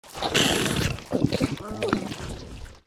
58aea60d01 Divergent / mods / Soundscape Overhaul / gamedata / sounds / monsters / bloodsucker / eat_3.ogg 49 KiB (Stored with Git LFS) Raw History
eat_3.ogg